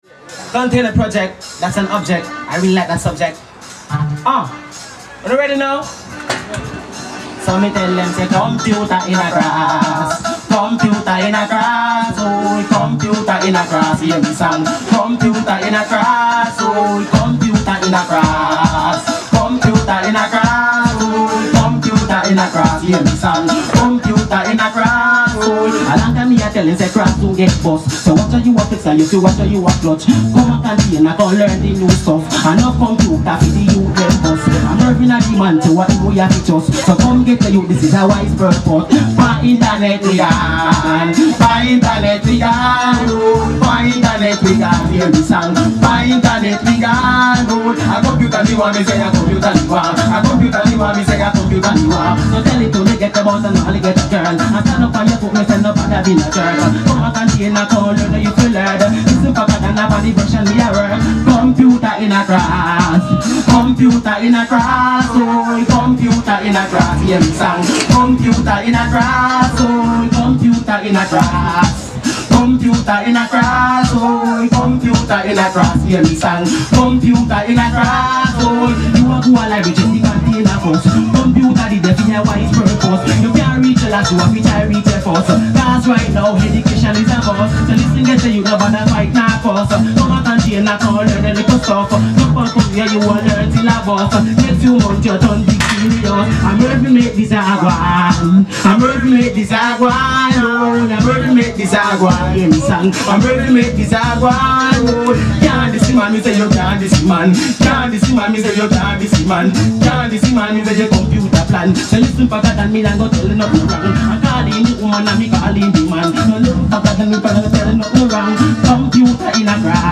A 3min 44sec video that mixes a performance by 'The Weblink Crew' at the launch of The Container, with video taken during the installation of the computer systems.
2.7 megabyte MP3 file of the audio from the Weblink Crew's performance.